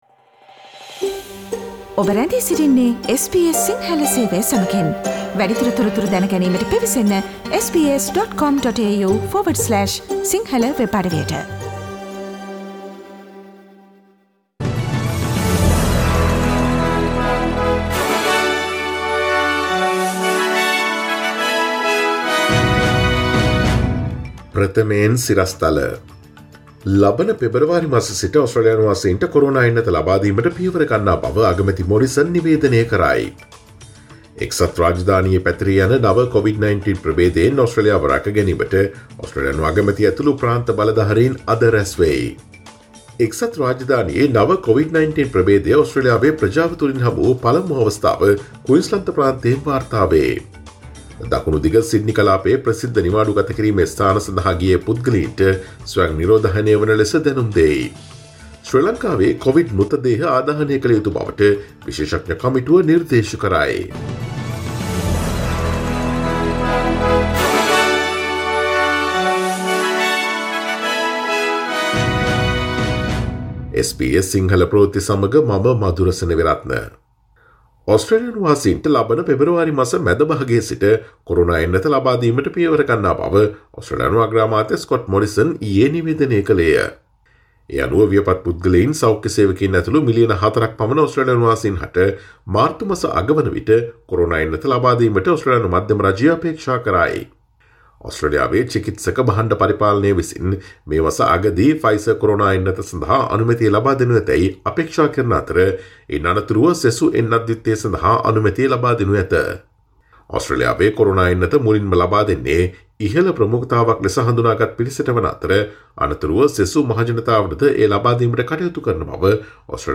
Today’s news bulletin of SBS Sinhala radio – Friday 08 January 2021